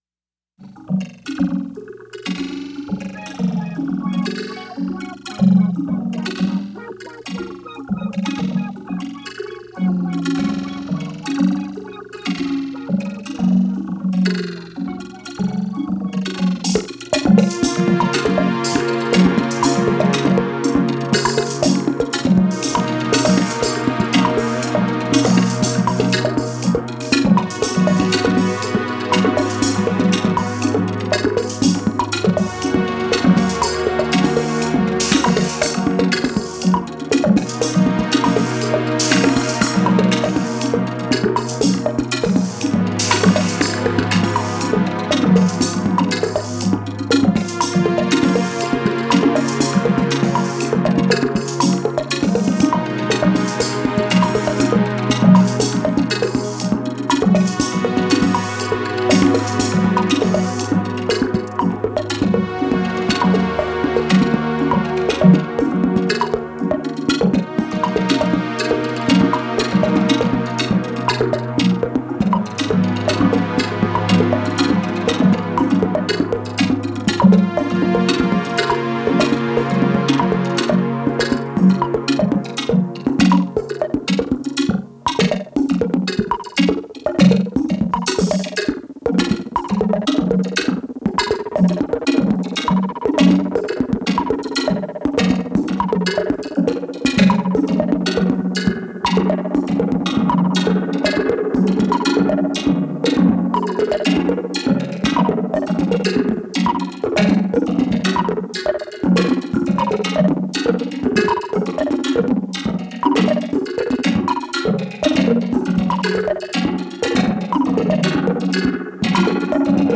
synthétiseurs modulaires
hypnotic, dreamy pieces